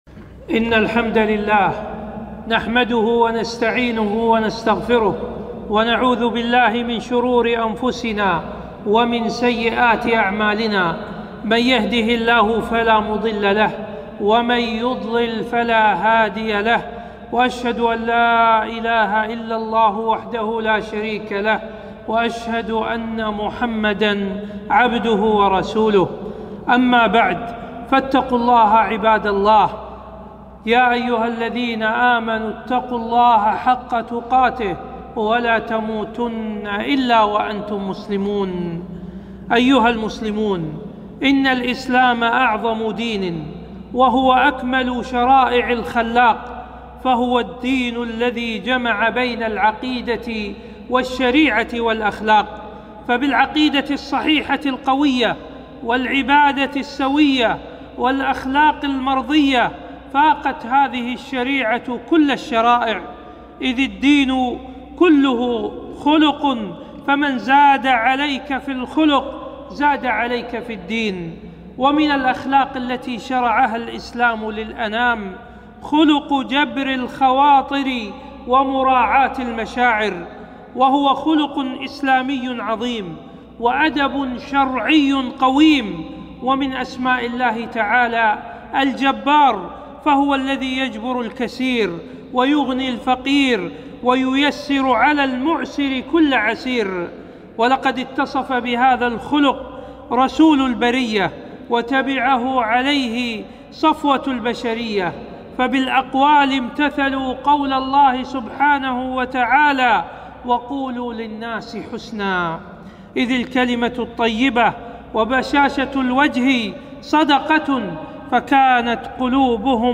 خطبة - خطبة جبر الخواطر ومراعاة المشاعر